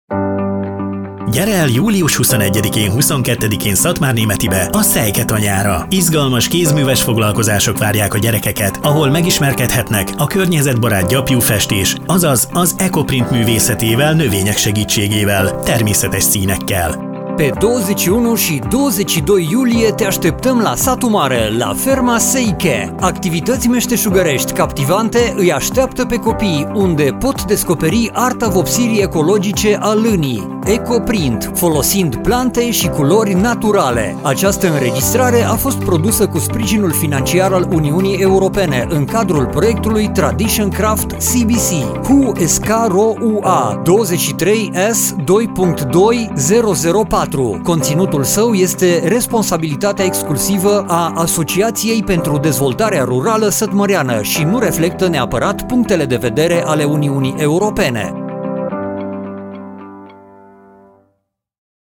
Radio spot - Atelier pentru copii - Kézműves műhely gyerekeknek